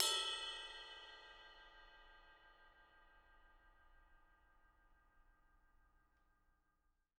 R_B Ride Bell 02 - Room.wav